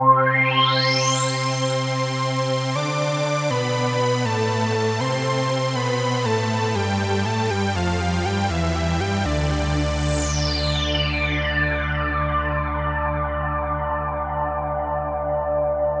Synth 33.wav